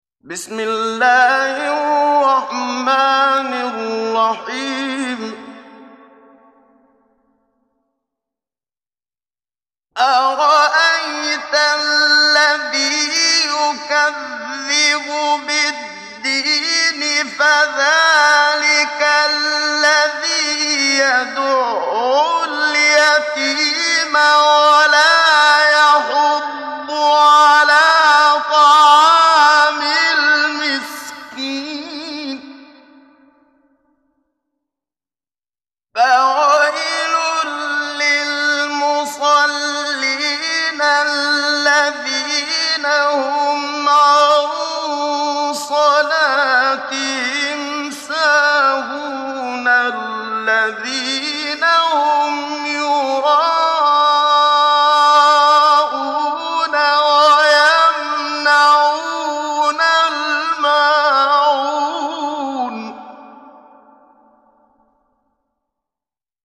محمد صديق المنشاوي – تجويد – الصفحة 9 – دعاة خير